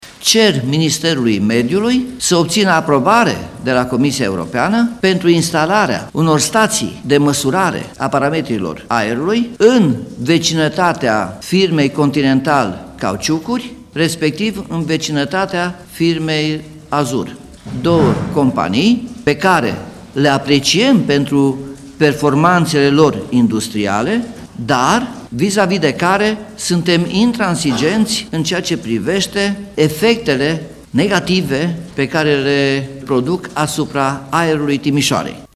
E vorba despre o fabrică de anvelope și una de vopsele – a spus primarul Nicolae Robu: